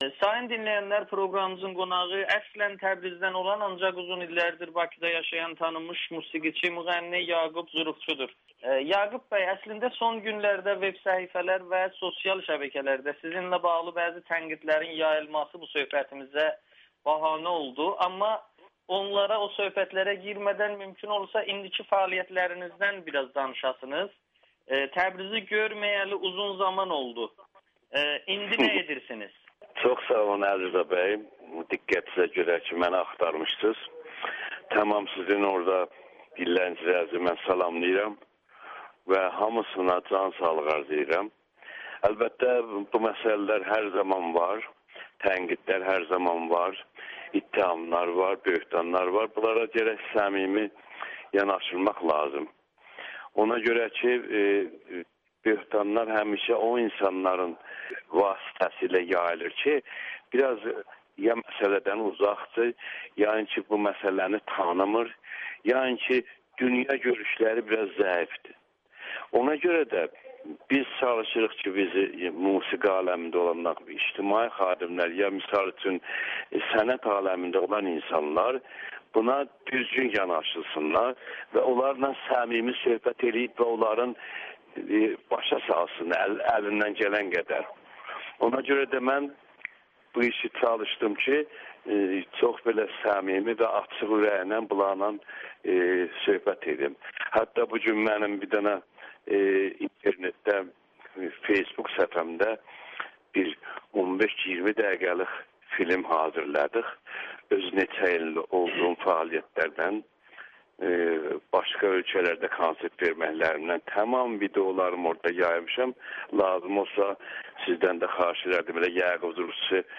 Yaqub Zurufçu: ‘Azəri’ və ‘Persian’ sözləri konsertimin posterindən götürüləcək [Audio-Müsahibə]
Müğənni Amerikanın Səsinə danışır